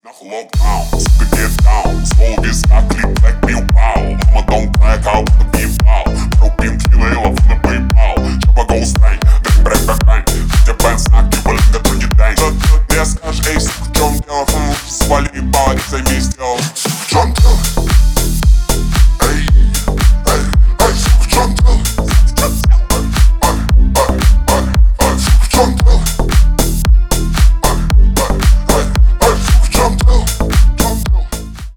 Ремикс # Рэп и Хип Хоп
клубные # громкие